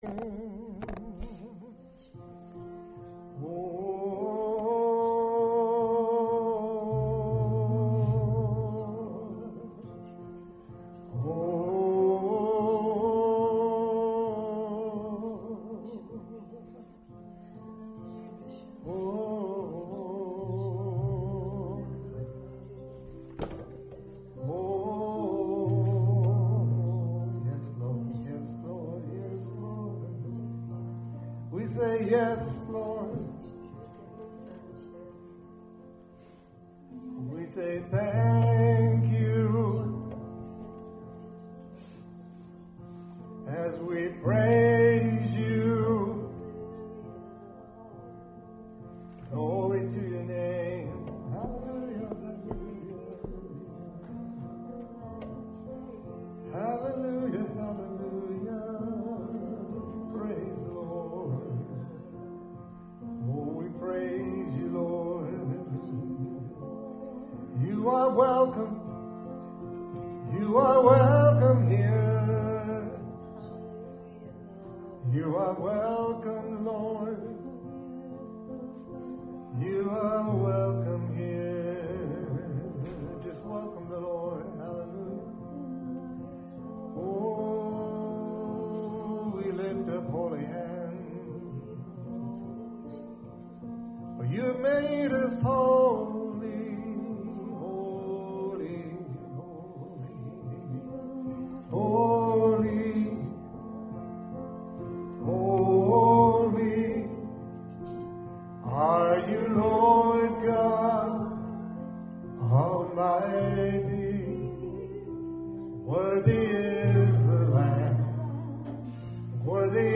WORSHIP 0419.mp3